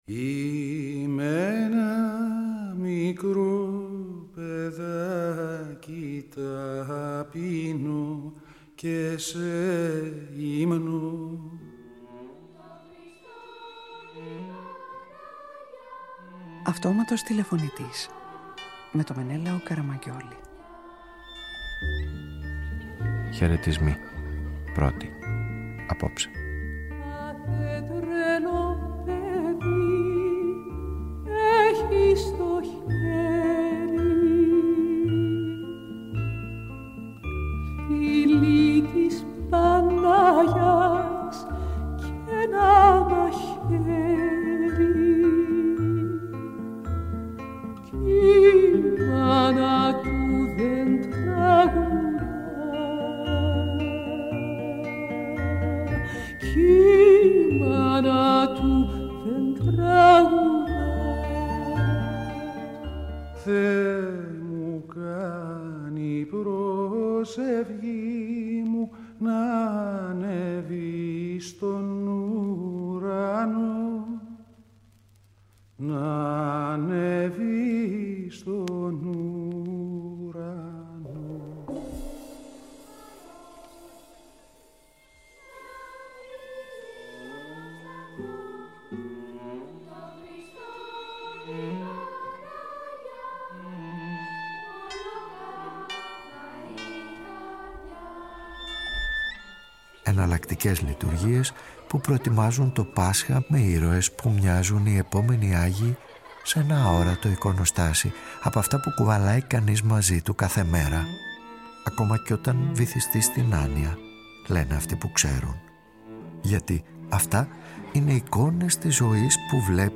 Jean-Paul Sartre Ραδιοφωνικη Ταινια